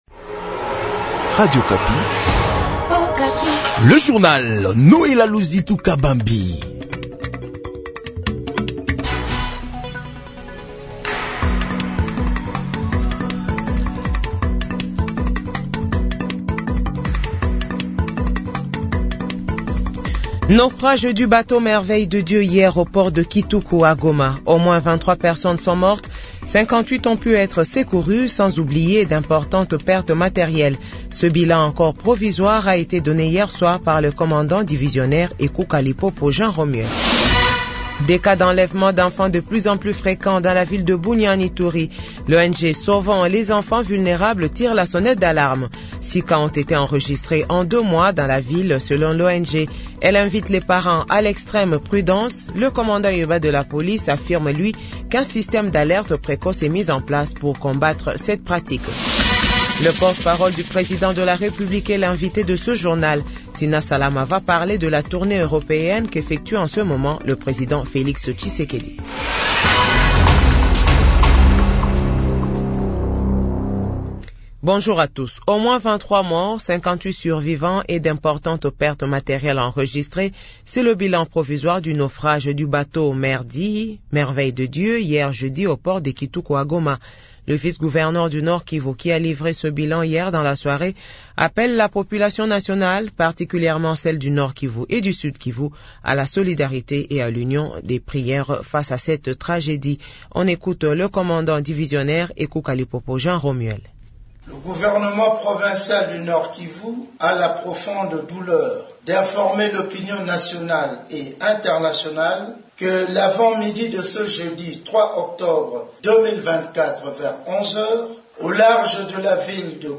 JOURNAL FRANÇAIS DE 8H00